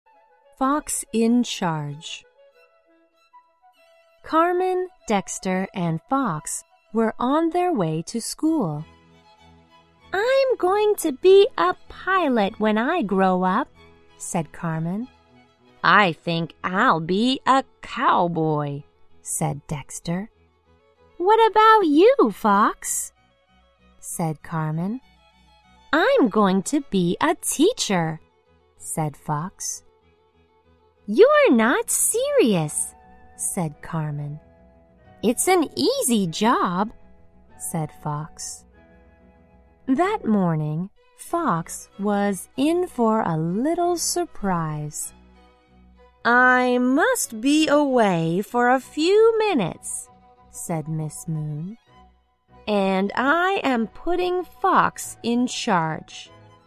在线英语听力室小狐外传 第38期:小狐管理的听力文件下载,《小狐外传》是双语有声读物下面的子栏目，非常适合英语学习爱好者进行细心品读。故事内容讲述了一个小男生在学校、家庭里的各种角色转换以及生活中的趣事。